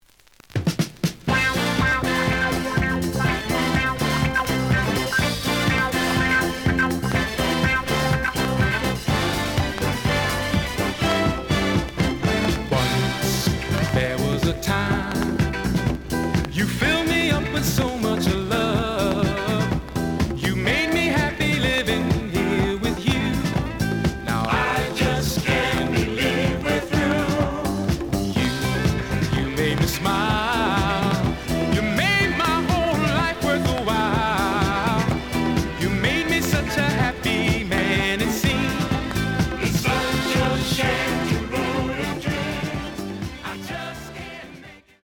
The audio sample is recorded from the actual item.
●Genre: Disco
Some click noise on beginnig of B side due to scratches.